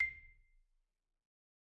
VSCO 2 CE 打击乐 马林巴琴 " 马林巴琴C7（马林巴琴打出的C6响声01
标签： C7 MIDI-速度-63 单票据 多重采样 打击乐器 MIDI音符-96 马林巴 vsco- 2
声道立体声